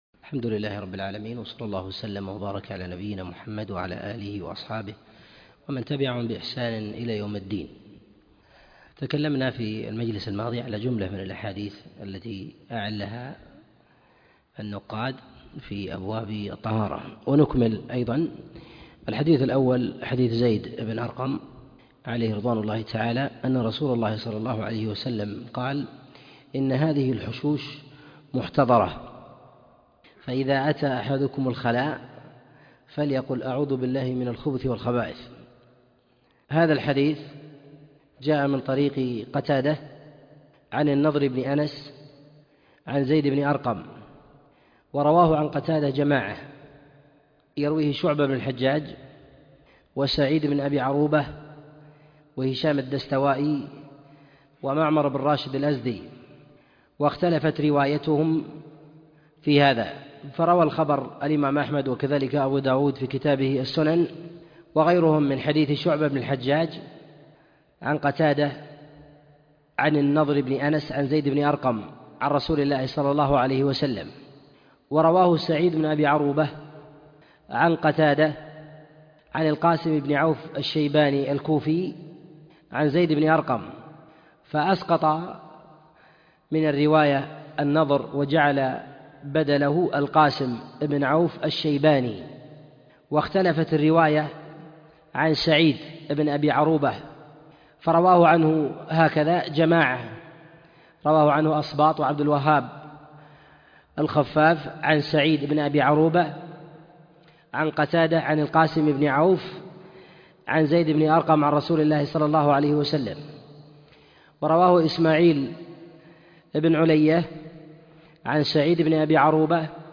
الأحاديث المعلة في الطهارة الدرس 8